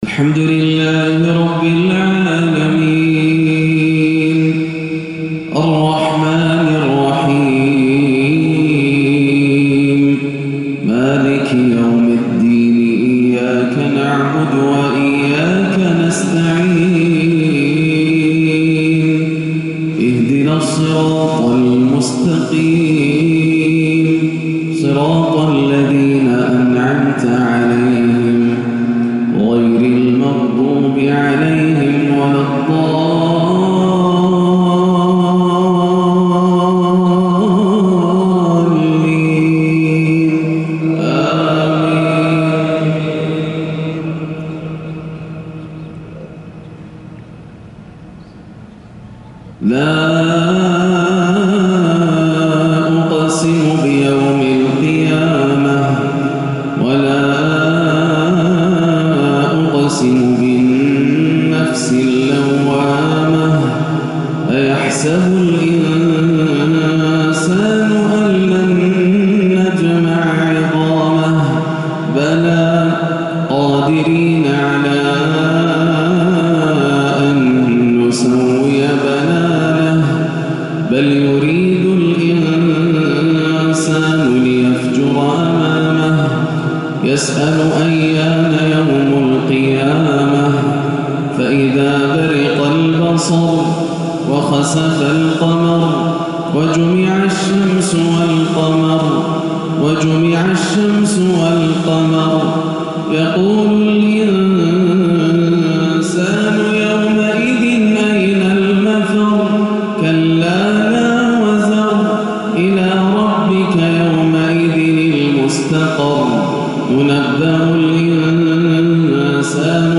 فجر السبت 7-1-1438هـ سورتي القيامة و الشمس > عام 1438 > الفروض - تلاوات ياسر الدوسري